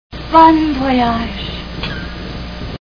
Some Like it Hot Movie Sound Bites